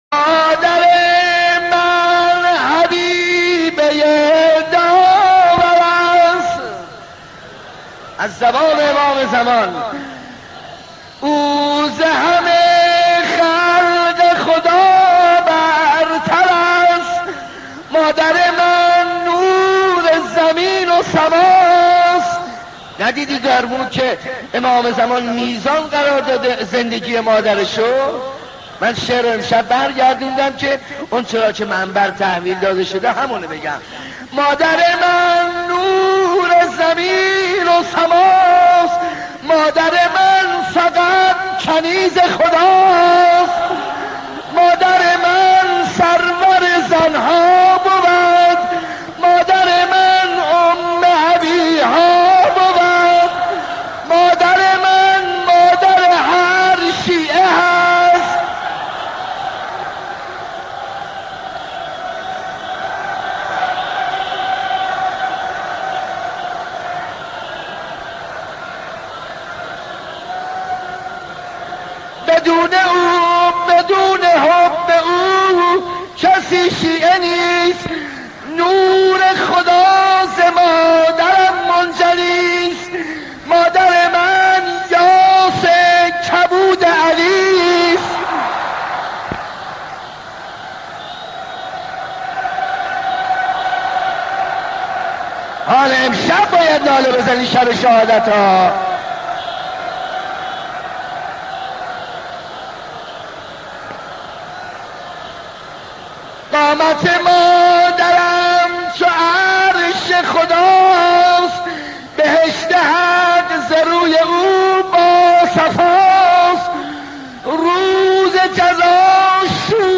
نوحه شهادت حضرت زهرا (س)
در شب شهادت حضرت